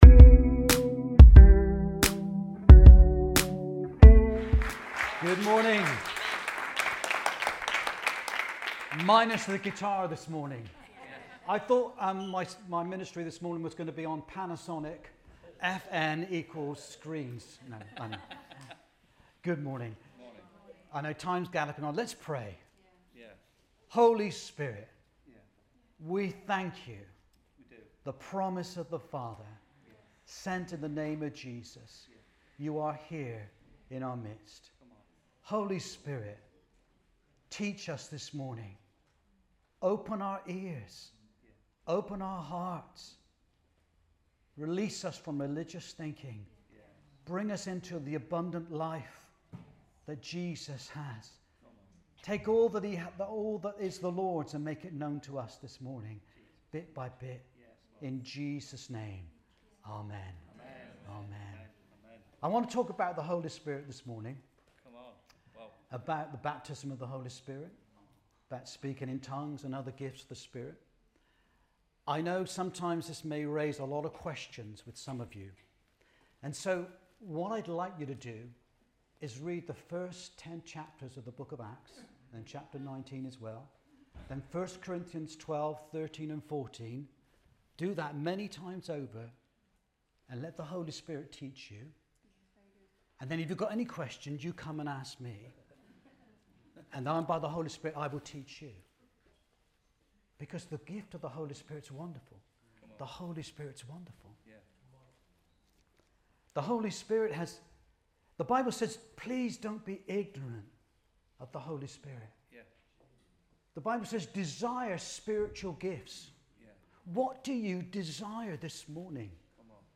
Sunday Messages Baptism in the Holy Spirit